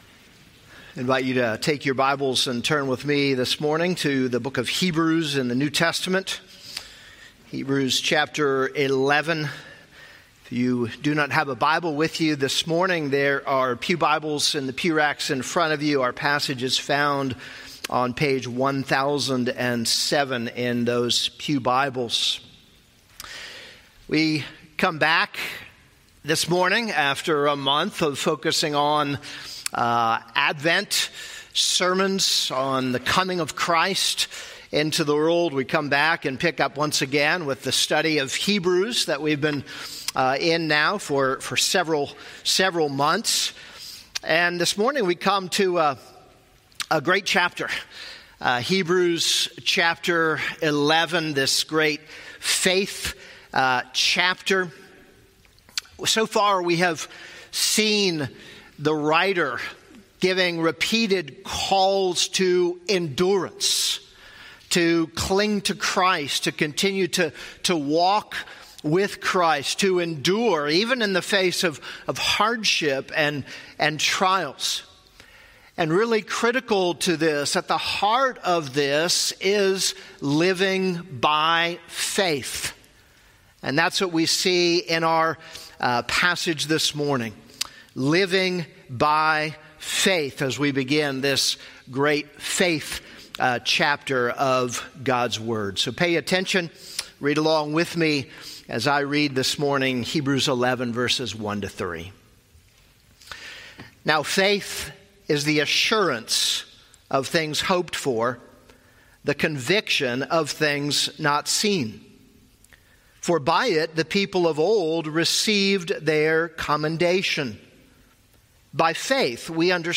This is a sermon on Hebrews 11:1-3.